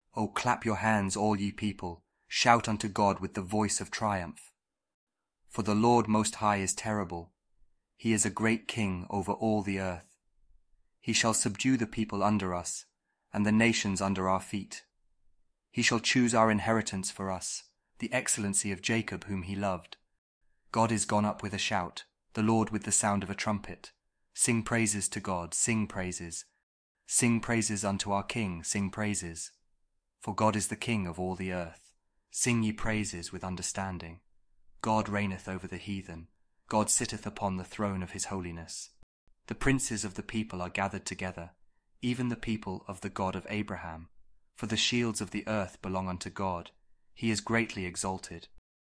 Psalm 47 | King James Audio Bible